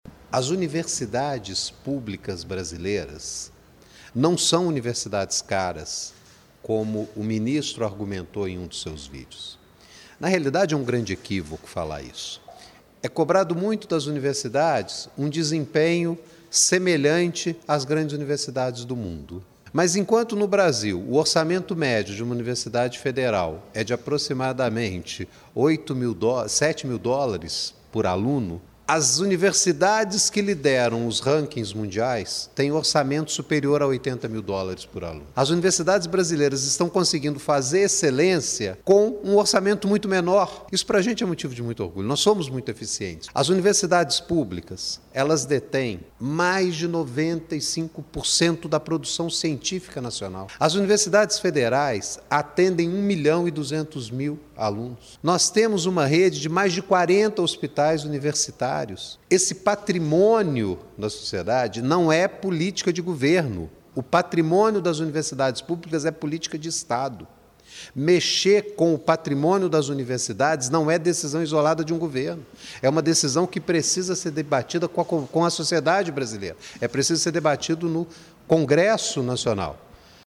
reitor da UFJF, Marcus David, analisa o impacto sobre os cortes